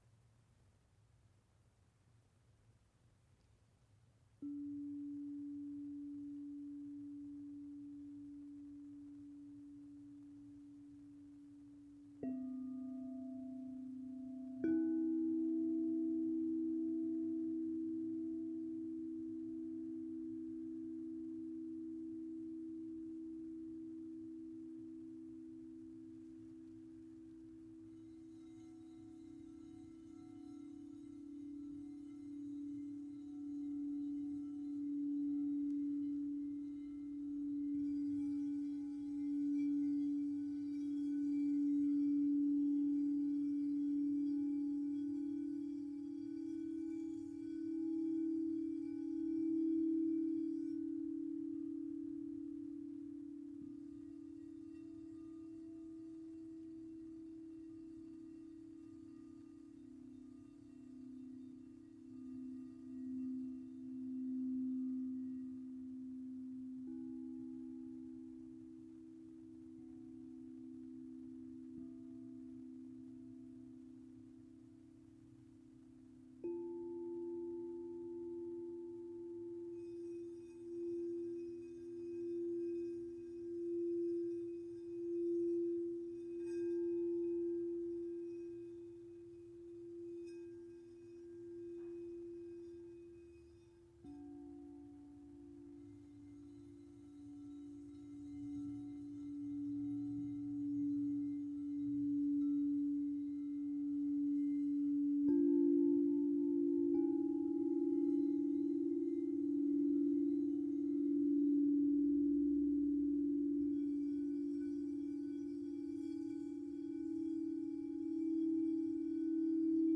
Jede der sieben Kristallklangschalen, die ich hier zum Klingen bringe, ist einem der sieben Hauptchakren zugeordnet: Wurzelchakra, Sakralchakra, Solarplexus, Herzchakra, Halschakra, das dritte Auge und Kronenchakra. Bei der Session habe ich mich intuitiv leiten lassen, um die größtmögliche Harmonie und Schwingung einfließen zu lassen.
7_Chakren_Klangreise_final.mp3